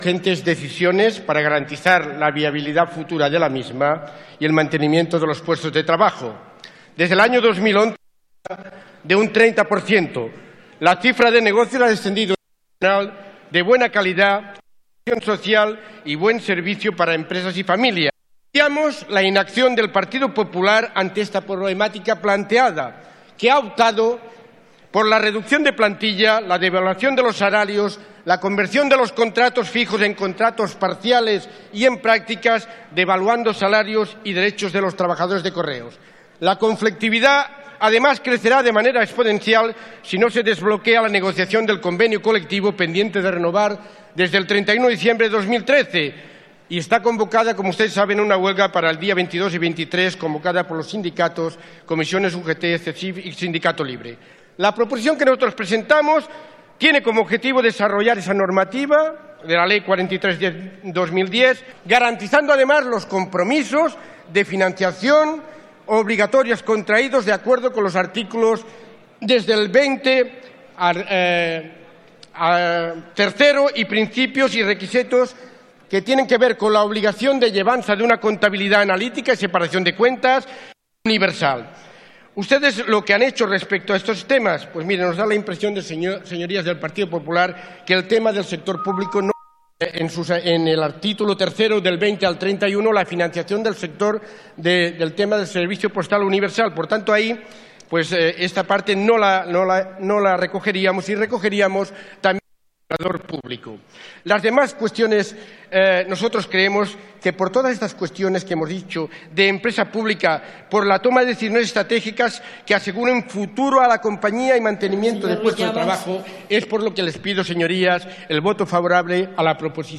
Fragmento de la intervención de Román Ruiz en el Congreso en defensa de una proposición no de ley para garantizar el servicio postal universal y defender a la sociedad estatal de correos y telégrafos 9/12/2014